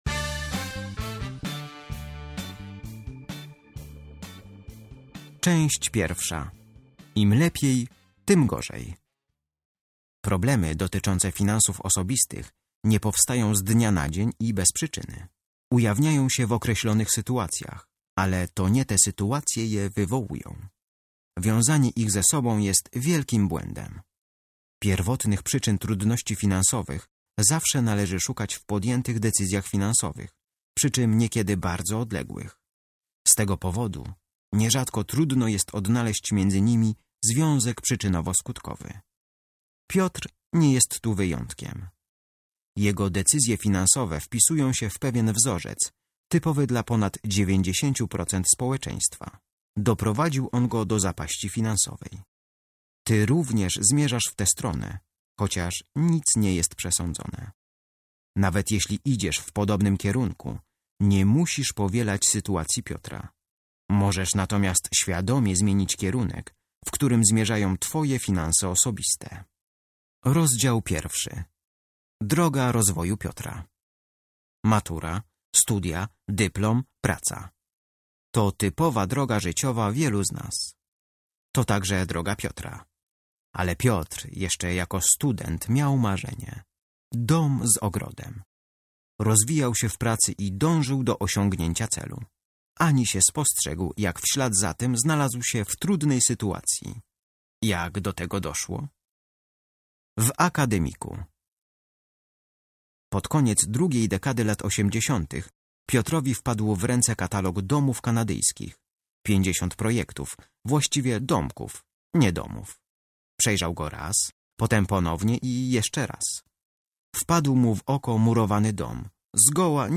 audiobook mp3 do pobrania
Lektor